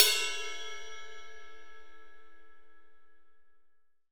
Index of /90_sSampleCDs/AKAI S6000 CD-ROM - Volume 3/Ride_Cymbal2/JAZZ_RIDE_CYMBAL